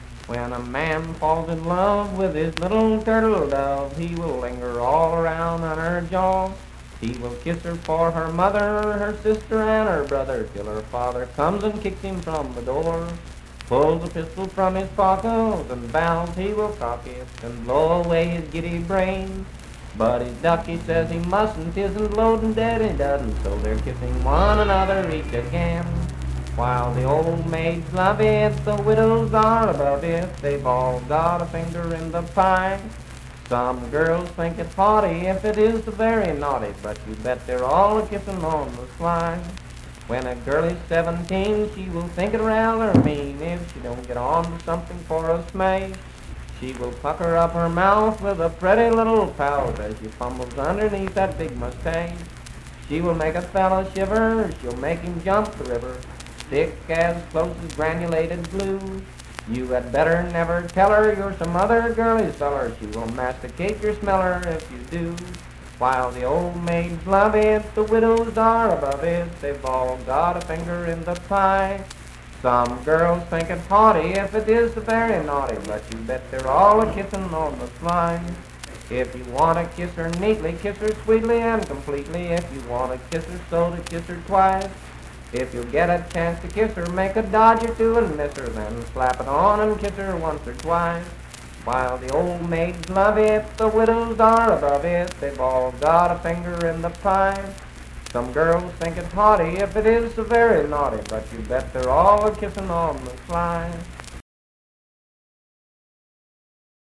Unaccompanied vocal performance
Voice (sung)
Roane County (W. Va.), Spencer (W. Va.)